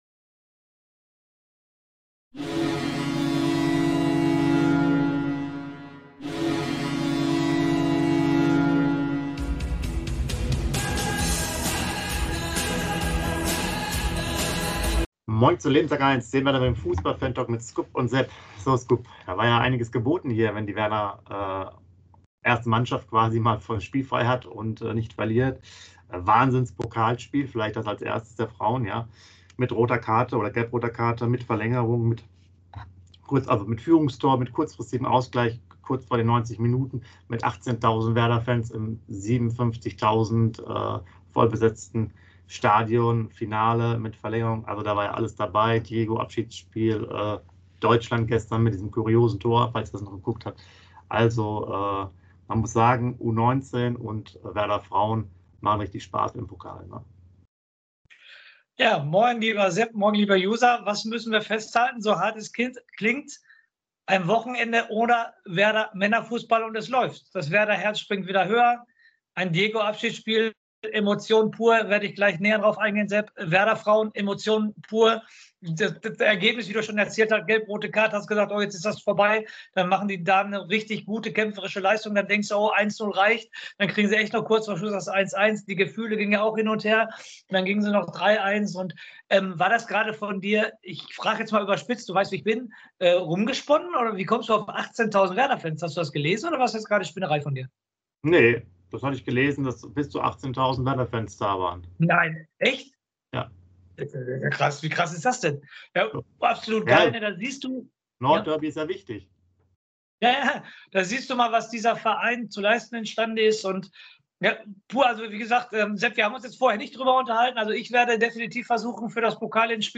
Dem Werder Bremen - Fantalk.